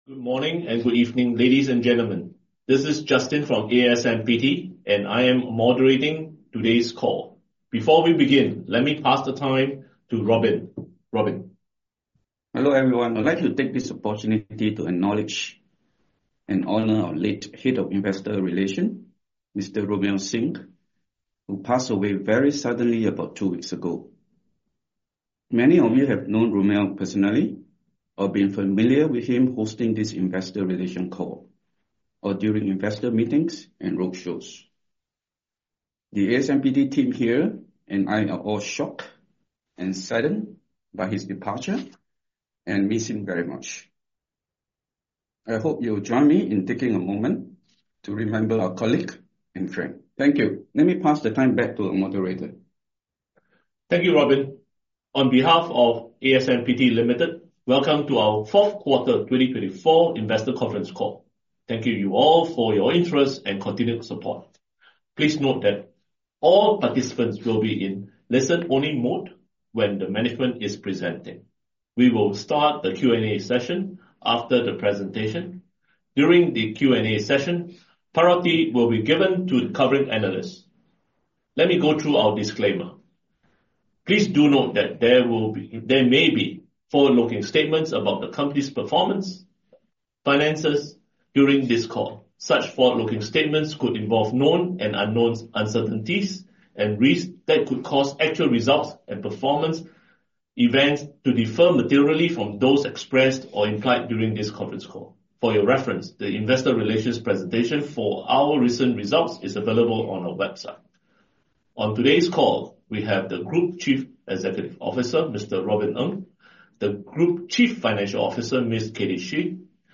asmpt_4q2024_conference_callrecording.mp3